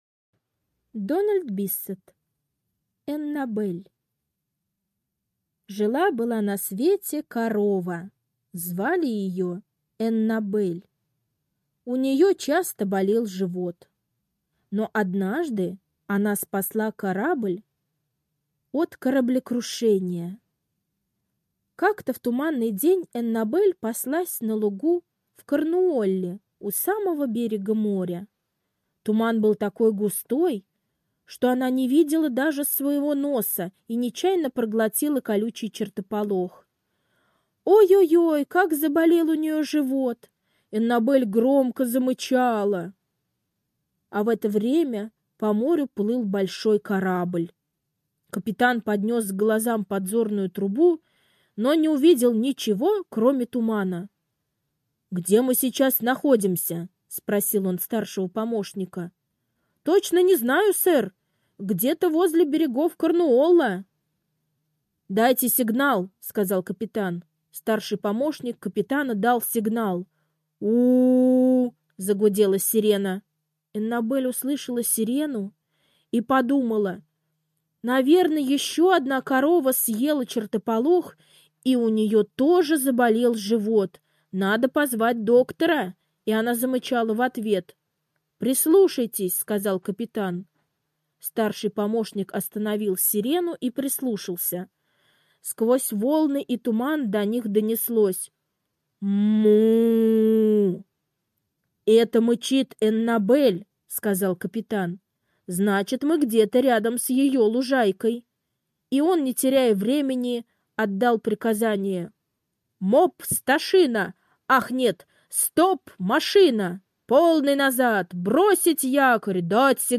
Эннабель - аудиосказка Биссета Д. Сказка про корову Аннабель, которая однажды спасла корабль от кораблекрушения.